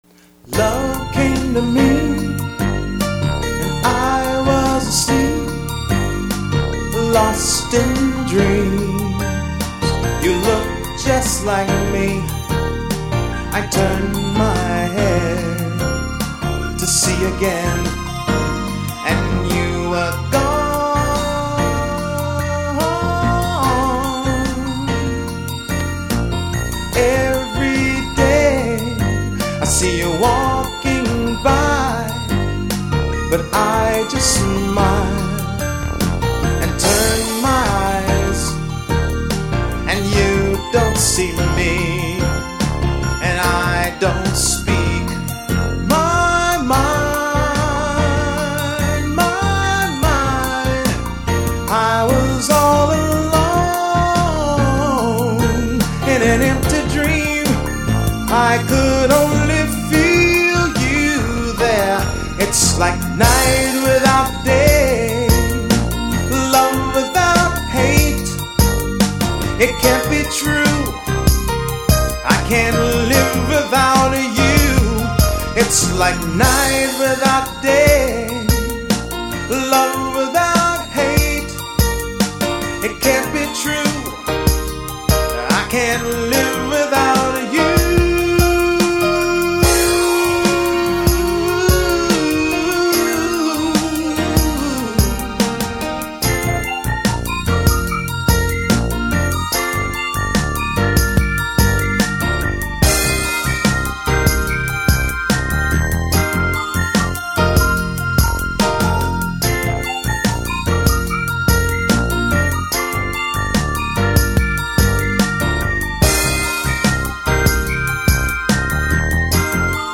vocals and keys
drum programming